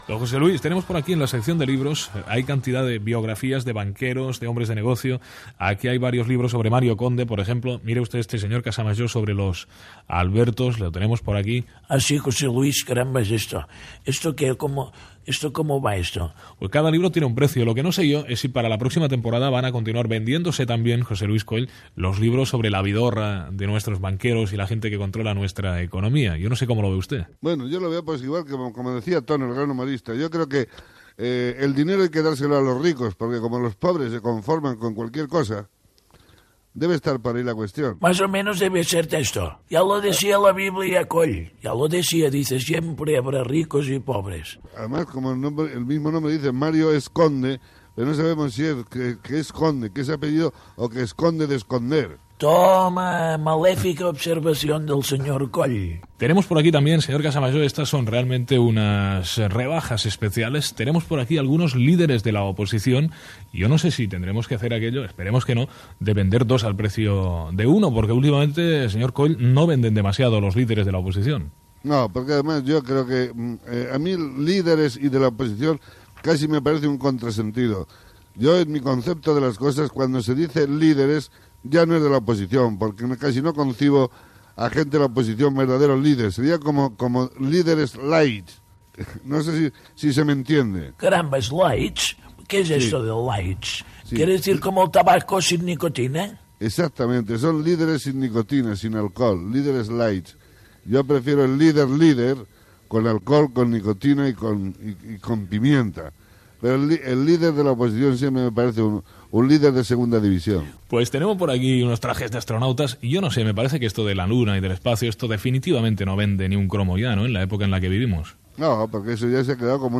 Entrevista a l'humorista José Luis Coll sobre alguns "productes" que es podrien rebaixar
Entreteniment